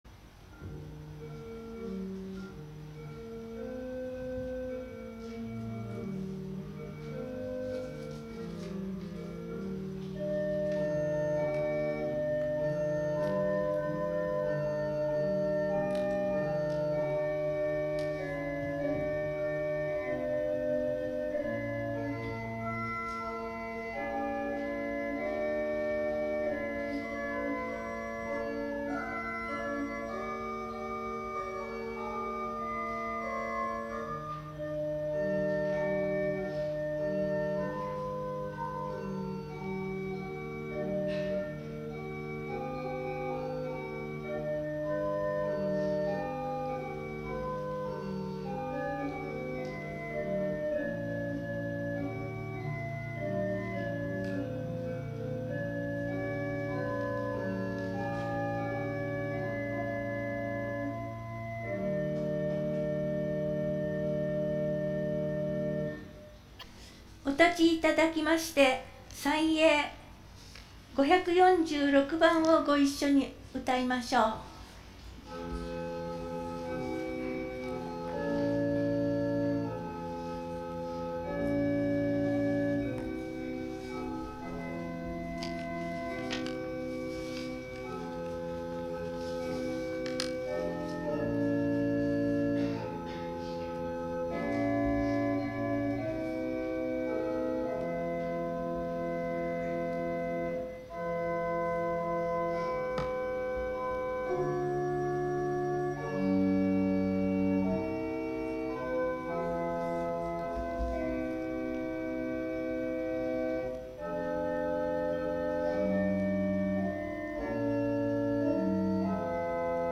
1月25日（日）主日礼拝録音